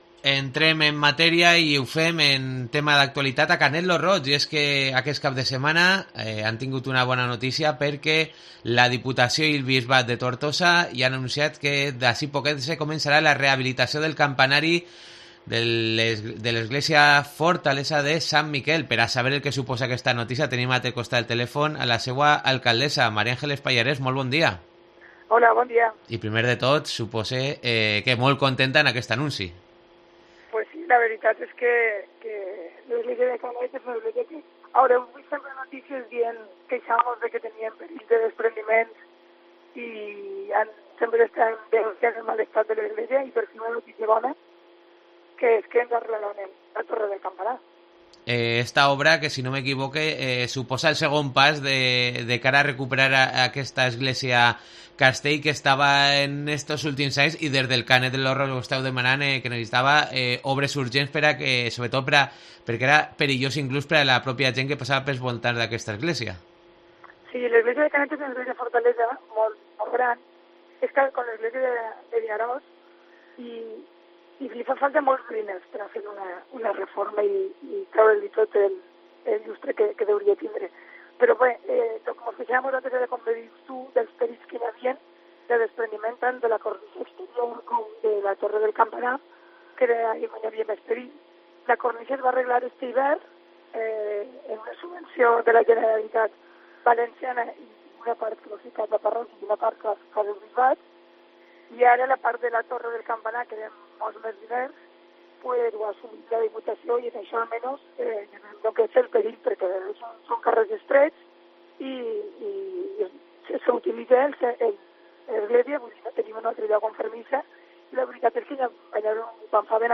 Entrevista a María Ángeles Pallarés (alcaldessa de Canet lo Roig)